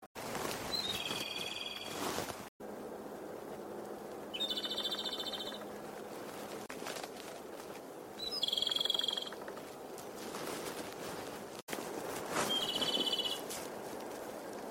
しかし諦めずに探鳥を行っていた処、湯の湖畔でコマドリの囀りが聞かれました。
コマドリの囀り
komadori01.mp3